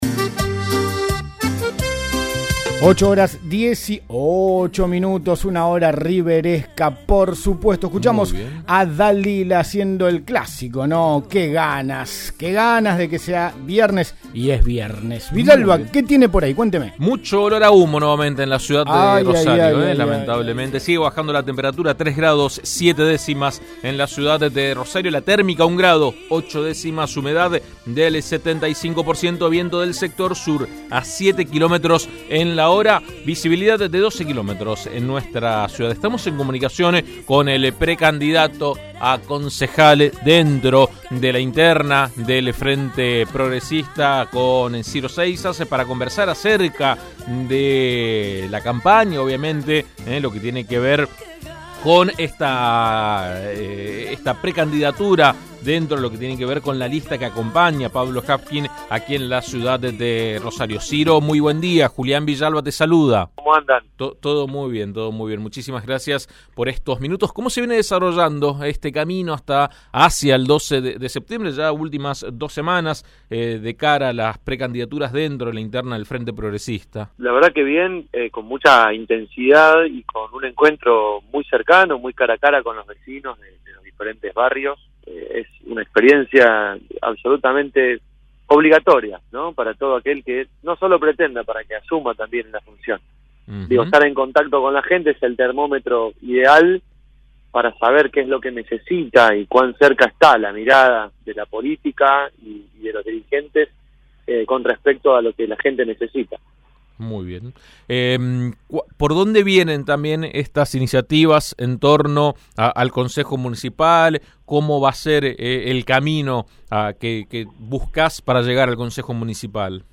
en diálogo con AM 1330 explicó sus propuestas.